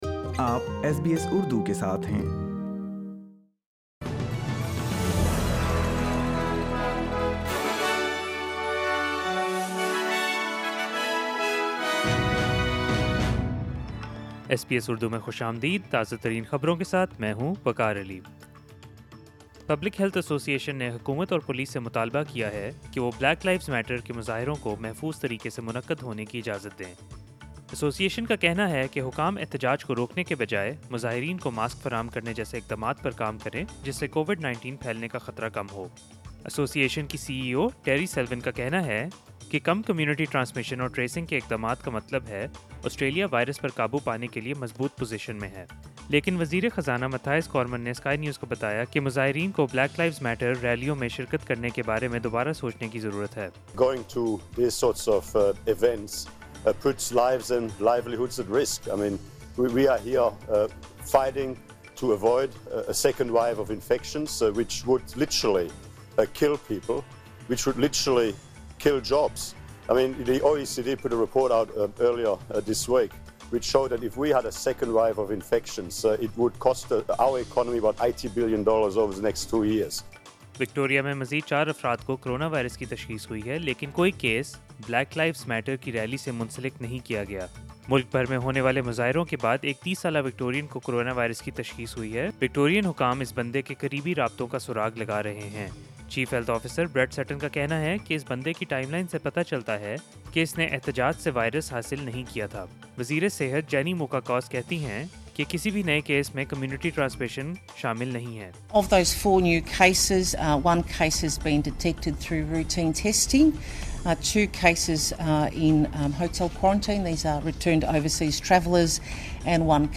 SBS Urdu News 12 June 2020